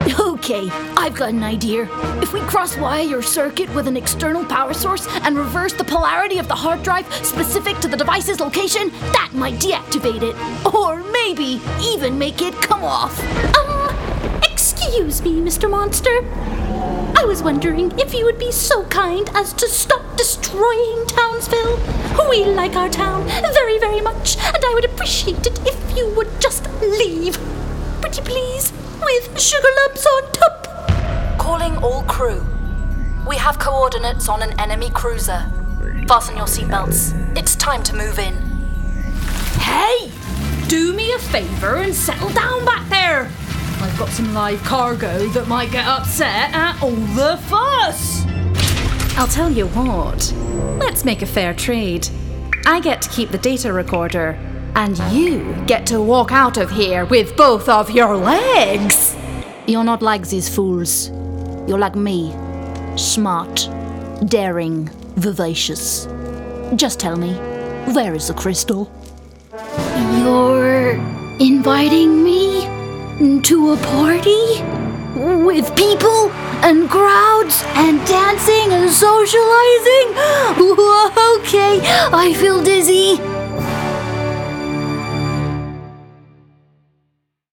• Native Accent: Bristol, RP
• Home Studio